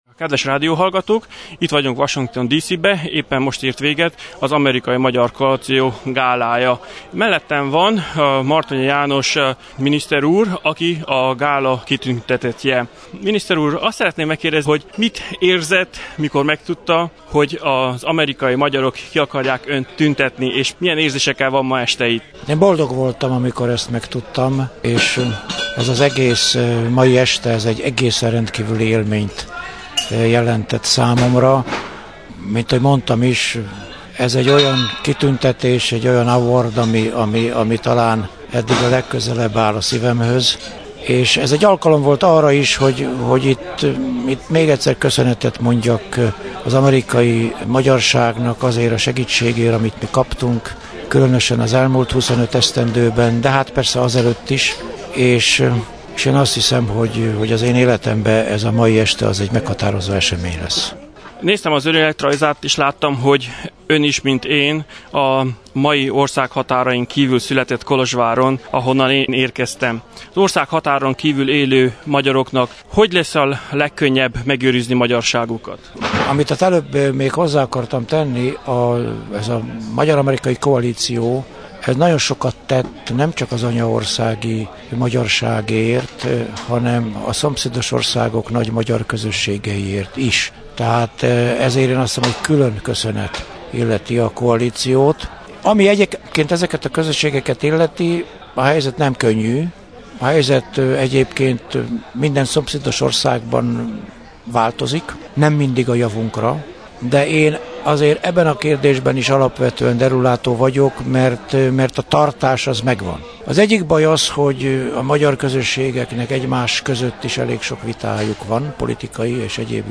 A rendezvény végén sikerült egy rövid interjút készítenem az est kitüntetettjével, Mártonyi János miniszter úrral: